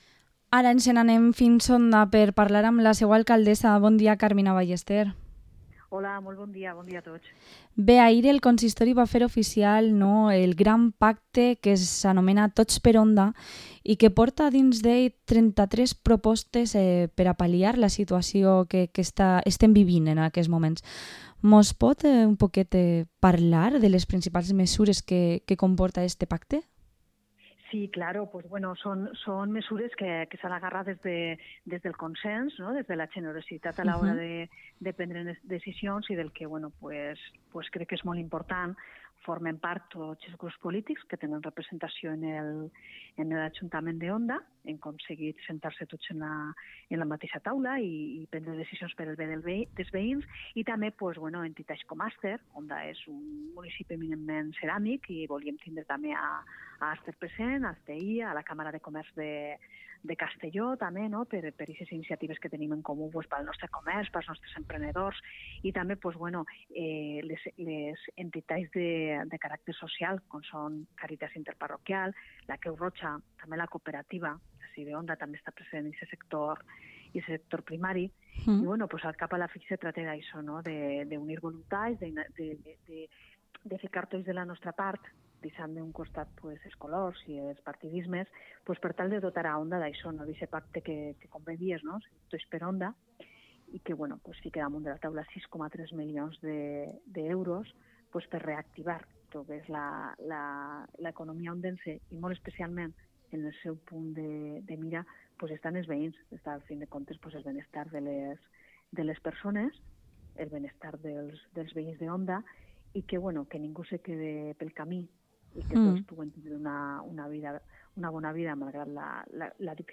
Entrevista a Carmina Ballester, Alcaldesa de Onda